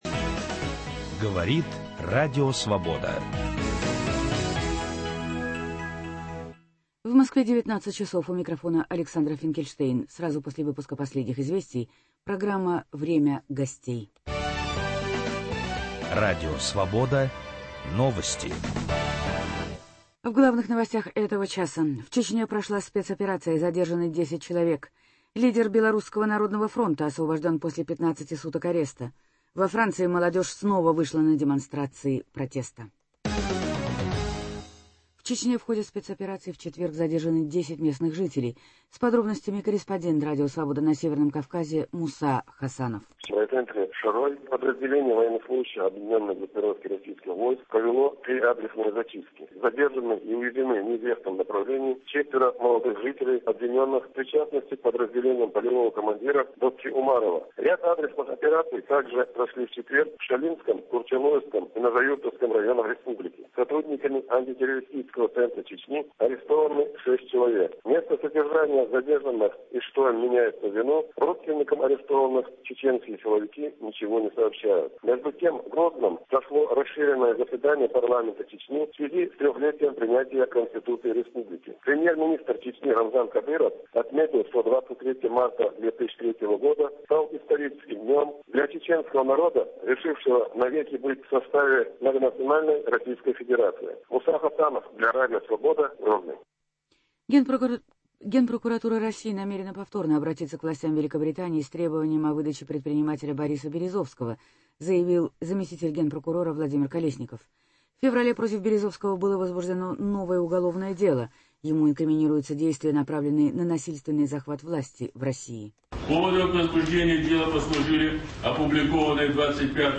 Ситуация в Белоруссии после президентских выборов – на телеэкране и глазами очевидцев. В программу приглашены вернувшийся из Минска депутат государственной думы Владимир Рыжков и руководитель отдела документальных и спецпроектов Первого канала Павел Шеремет.